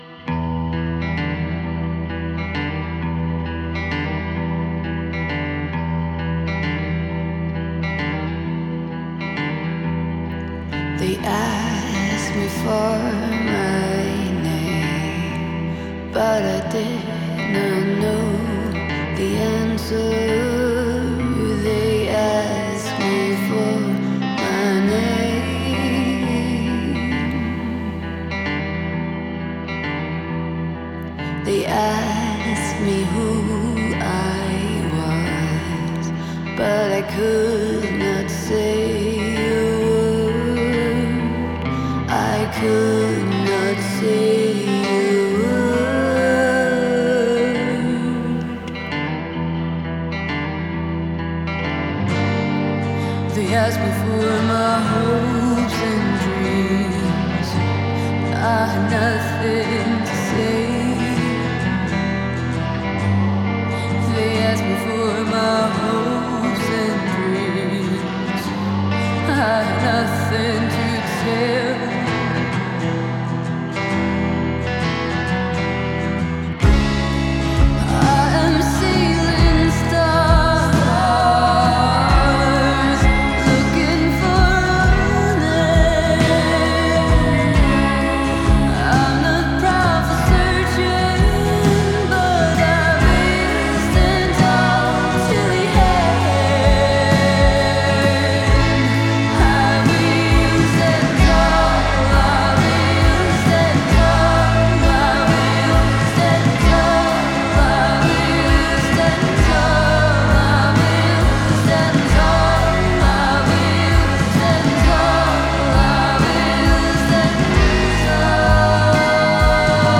Genre: Indie, Rock, Pop